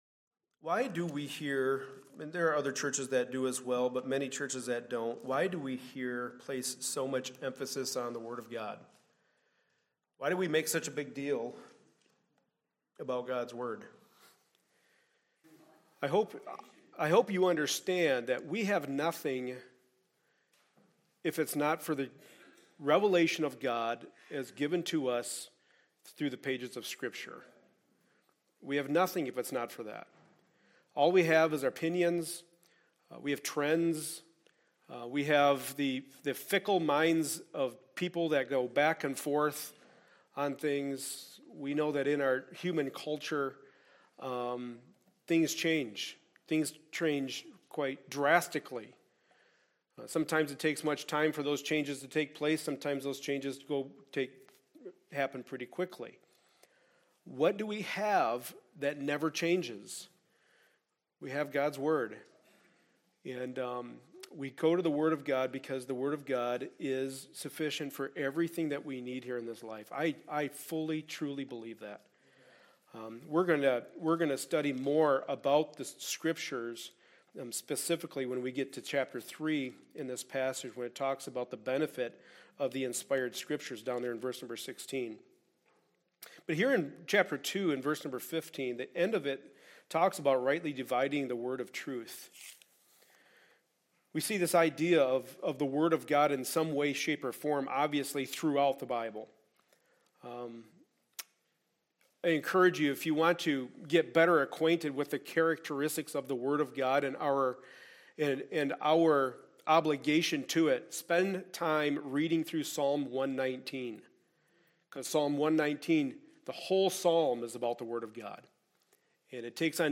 2 Timothy 2:14-15 Service Type: Sunday Morning Service A study in the Pastoral Epistles.